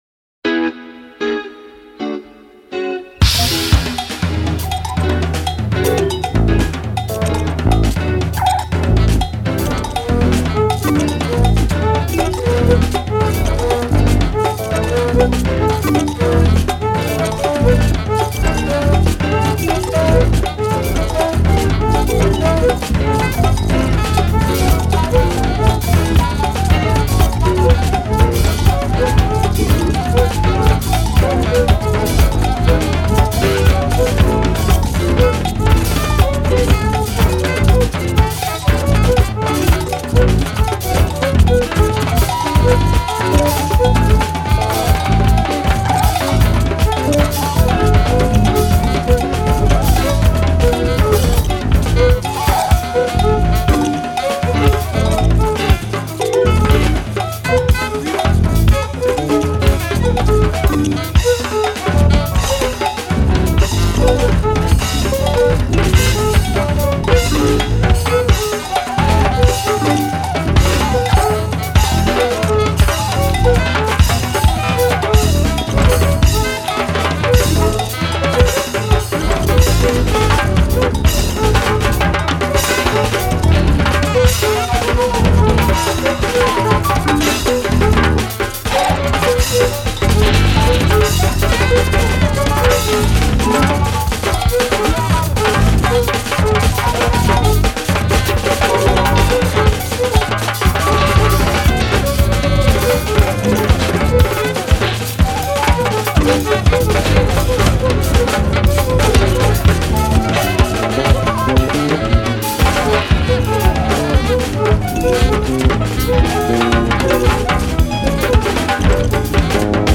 improvisations poly-métriques
batterie